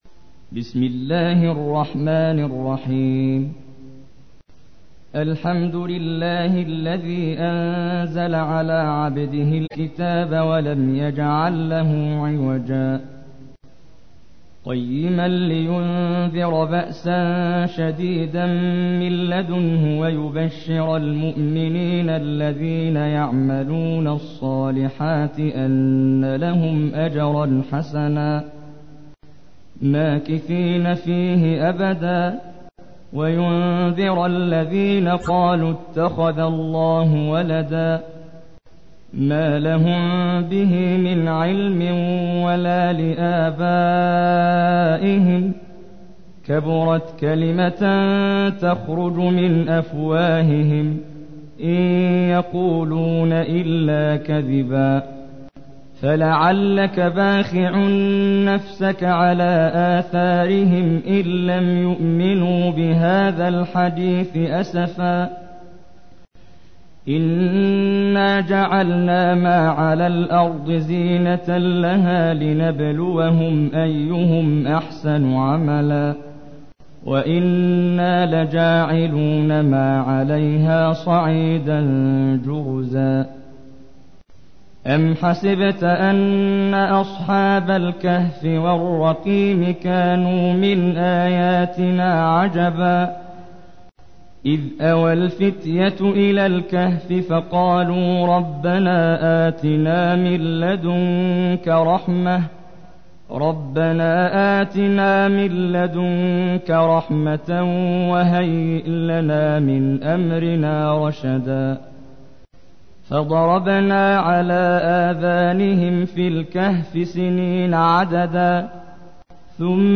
تحميل : 18. سورة الكهف / القارئ محمد جبريل / القرآن الكريم / موقع يا حسين